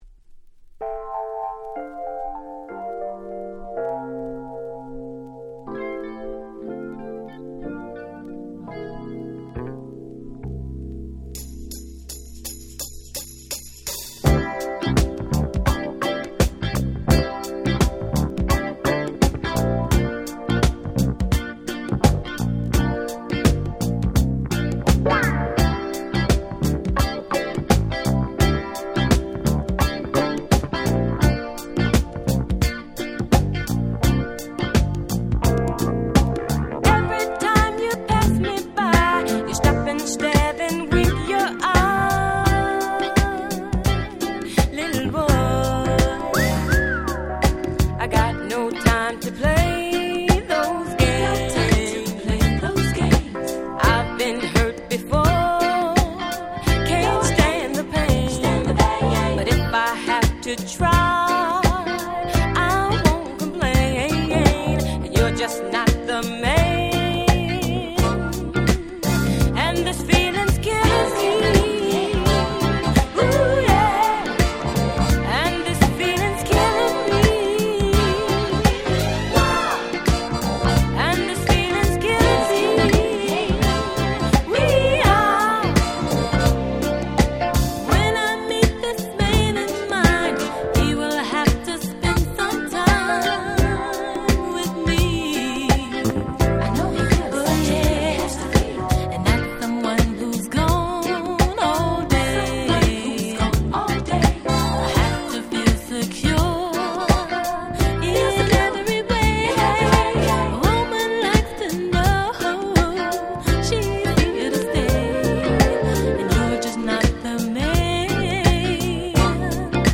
81' Super Soul Classics !!
Disco ディスコ ソウル ダンクラ ダンスクラシックス Dance Classics